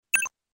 Efectos